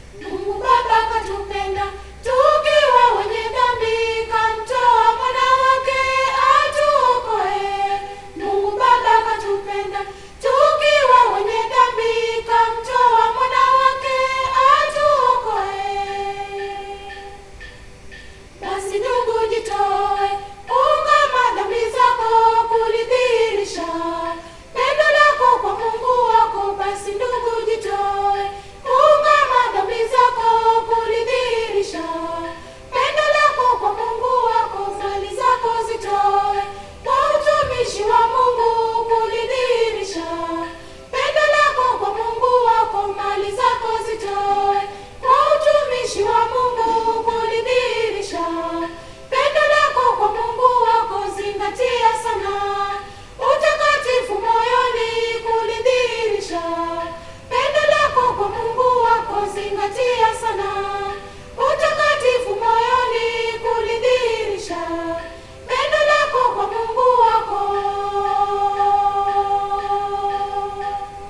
Key G